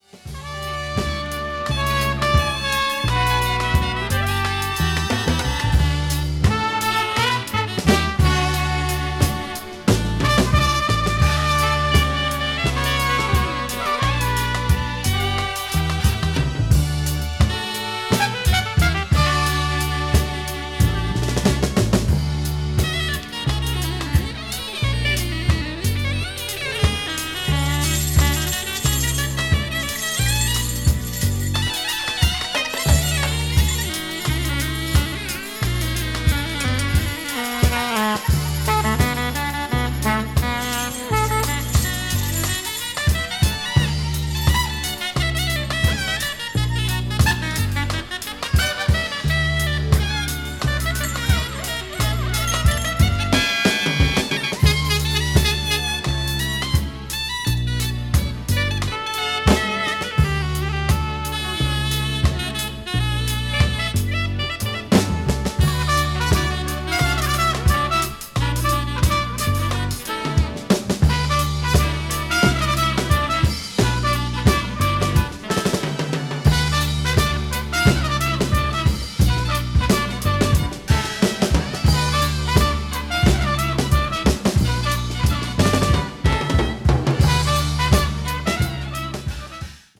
わずかにチリノイズが入る箇所あり